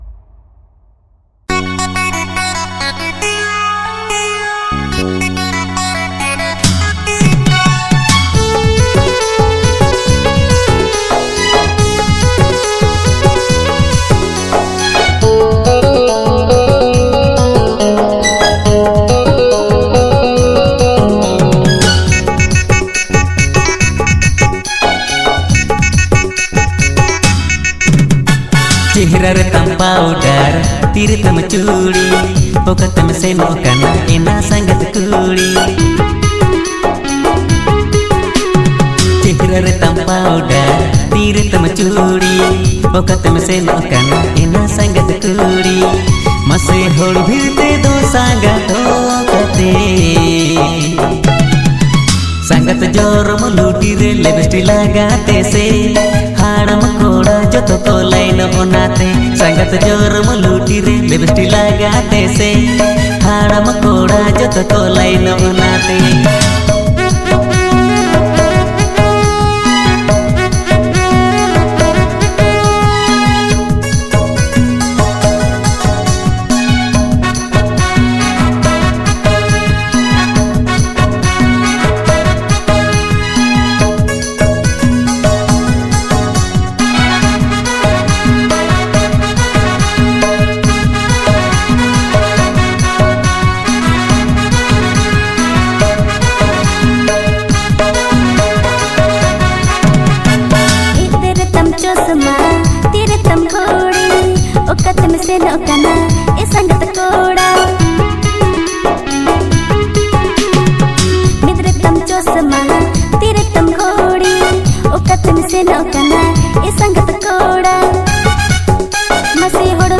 • Male Artist
• Female Artist